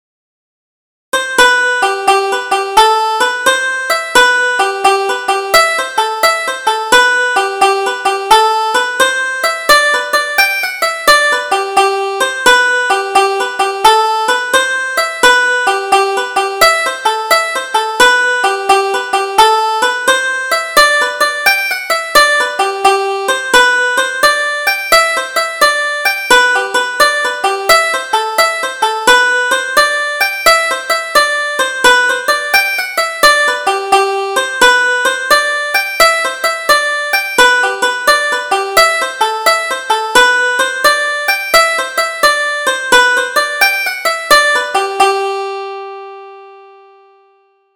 Double Jig: Kiss Me Sweetheart